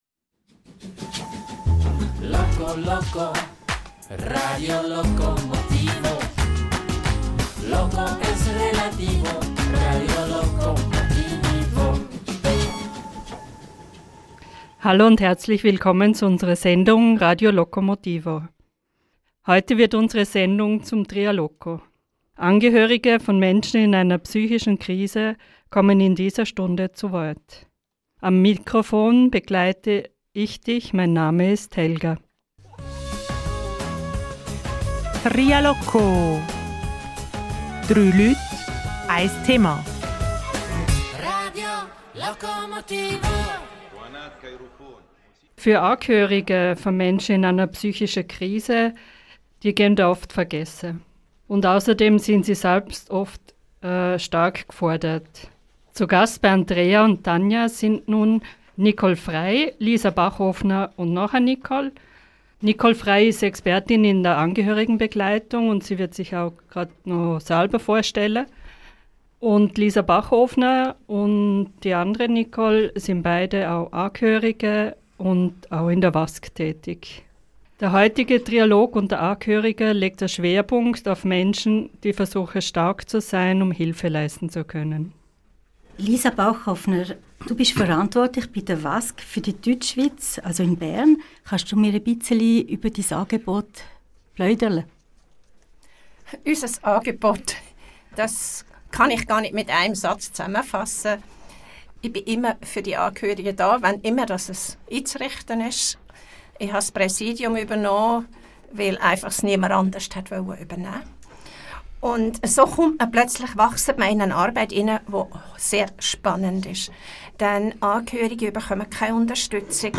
Über Möglichkeiten der Hilfe zur Selbsthilfe für Angehörige, sowie Vernetzungsmöglichkeiten, Austausch- und Hilfsangebote - darüber diskutieren wir im heutigen Trialog.